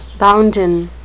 bounden (BOWN-den) adjective
X-Pronunciation: